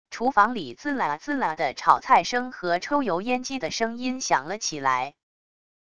厨房里滋啦滋啦的炒菜声和抽油烟机的声音响了起来wav音频